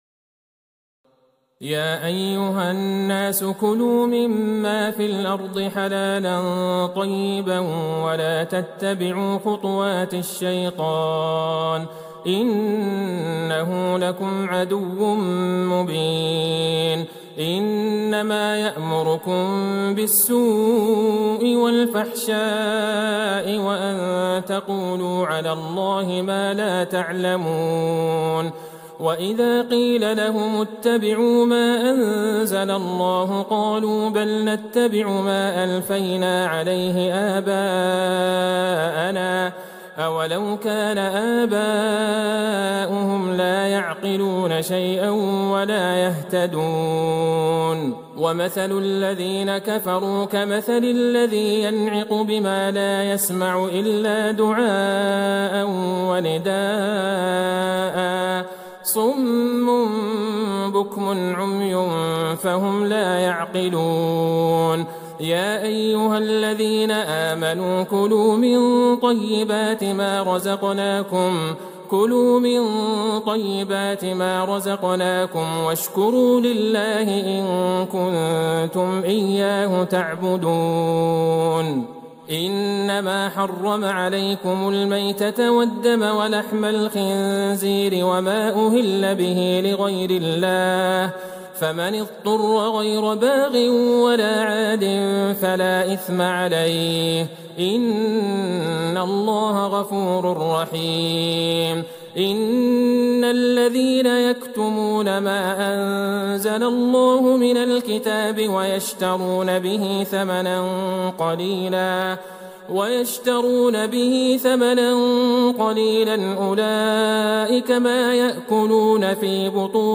ليلة ٢ رمضان ١٤٤١هـ من سورة البقرة {١٦٨-٢٠٢} > تراويح الحرم النبوي عام 1441 🕌 > التراويح - تلاوات الحرمين